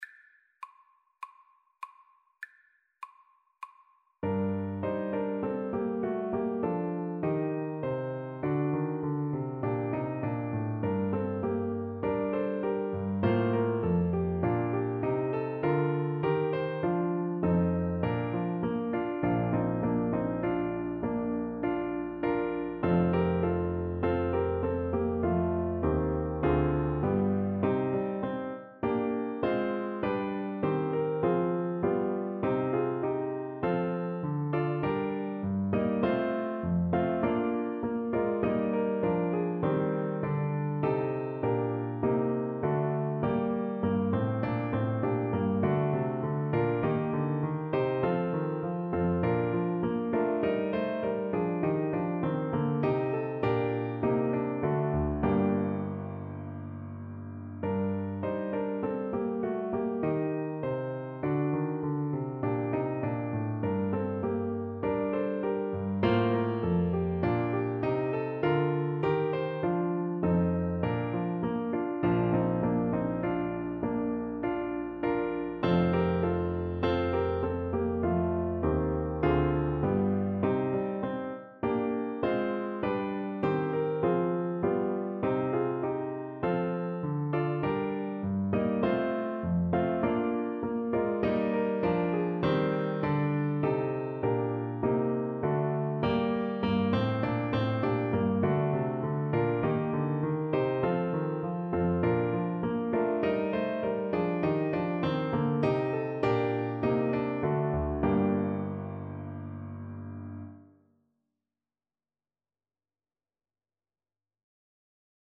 Play (or use space bar on your keyboard) Pause Music Playalong - Piano Accompaniment Playalong Band Accompaniment not yet available transpose reset tempo print settings full screen
Voice
G minor (Sounding Pitch) (View more G minor Music for Voice )
4/4 (View more 4/4 Music)
Classical (View more Classical Voice Music)